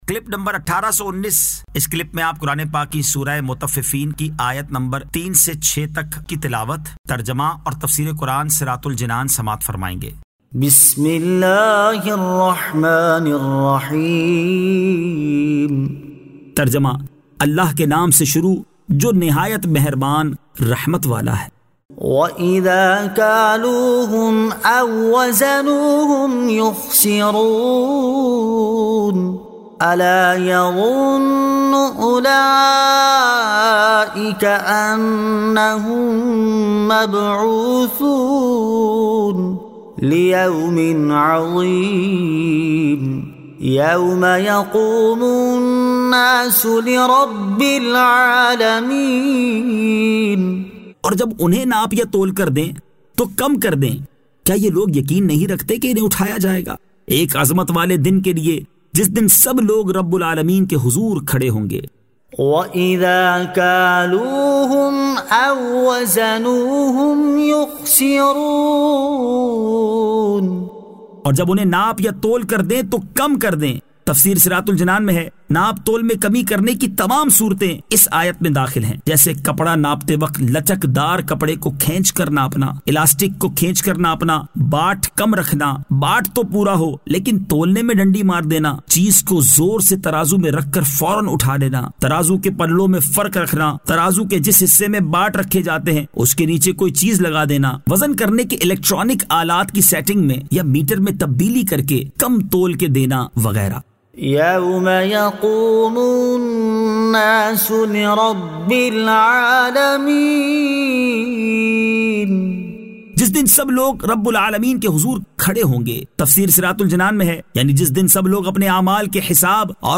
Surah Al-Mutaffifeen 03 To 06 Tilawat , Tarjama , Tafseer